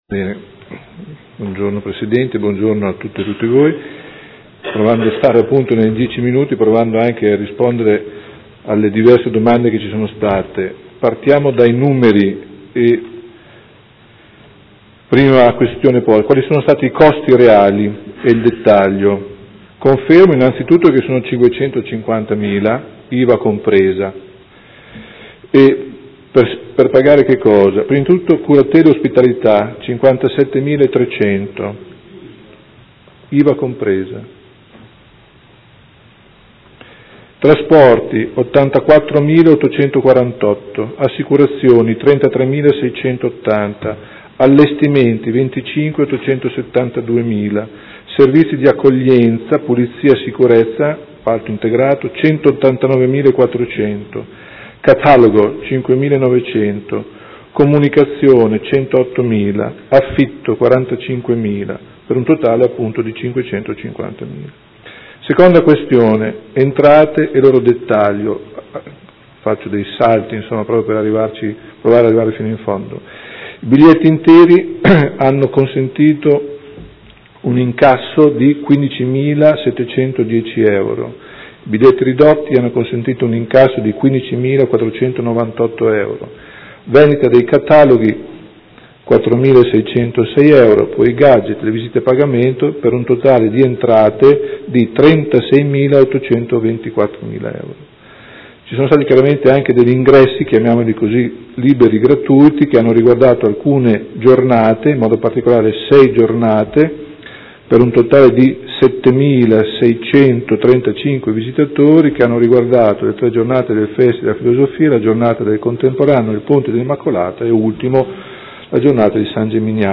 Seduta del 18/02/2016 Risponde a Interrogazione del Gruppo Consiliare Movimento 5 Stelle e del Consigliere Chincarini (Per Me Modena) avente per oggetto: Situazione Galleria Civica e MaTa e a Interrogazione del Gruppo Consiliare Per Me Modena avente per oggetto: Considerazioni sull’avvio dello spazio espositivo MaTa.